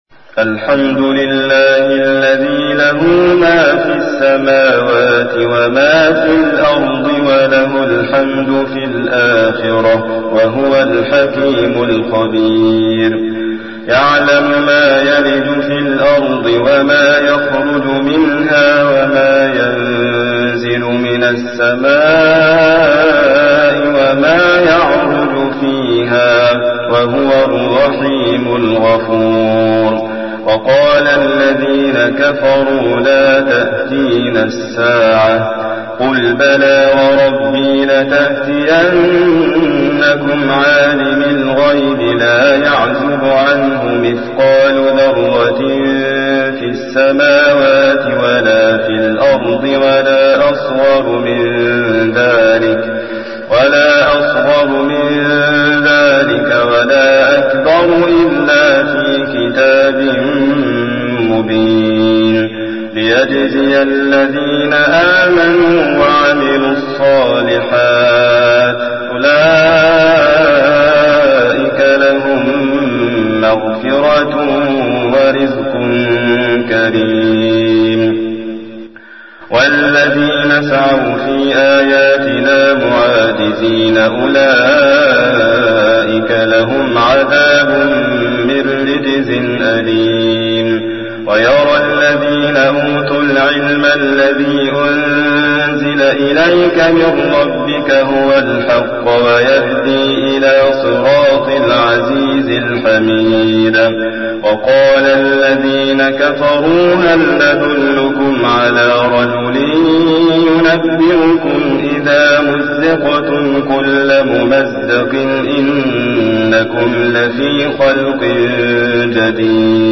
تحميل : 34. سورة سبأ / القارئ حاتم فريد الواعر / القرآن الكريم / موقع يا حسين